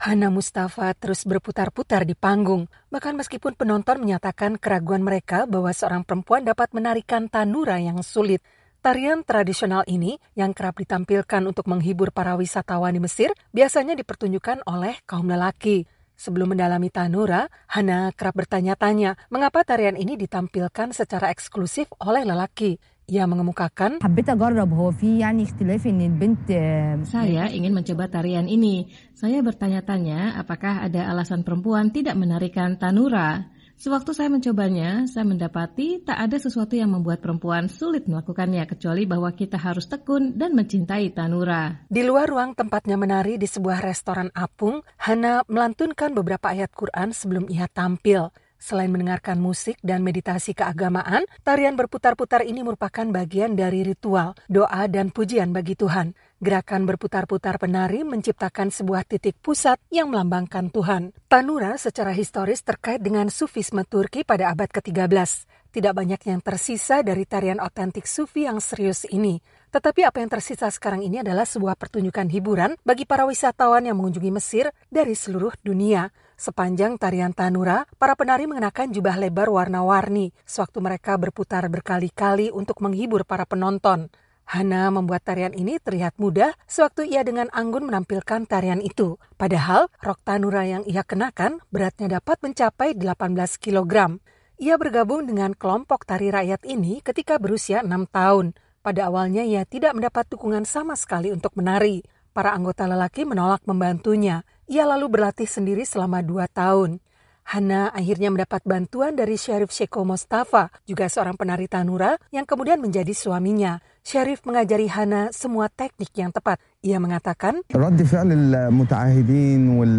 Seorang perempuan Mesir menarikan Tanoura, tarian yang biasanya hanya dilakukan laki-laki. Tanoura adalah ritus Sufi di mana penari ingin mengidentifikasi diri dengan Tuhan melalui musik yang menghipnotis dan gerakan yang melelahkan.